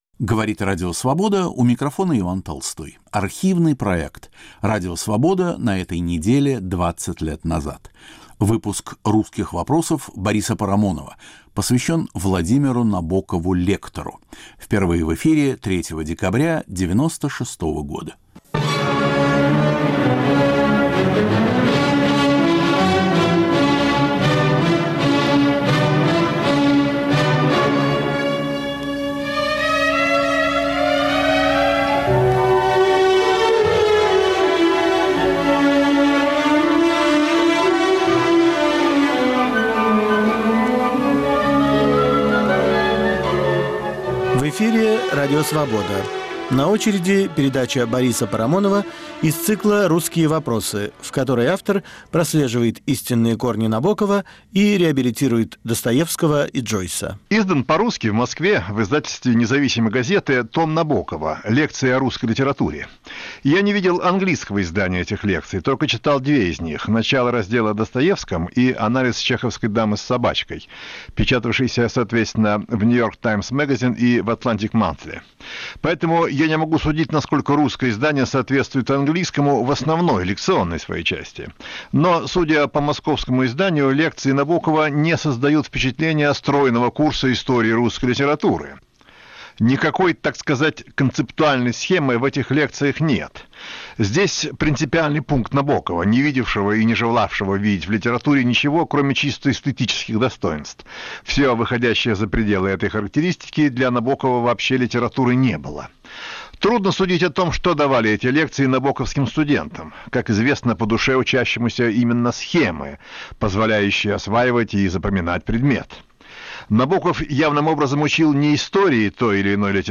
Радио Свобода на этой неделе 20 лет назад. Архивный проект.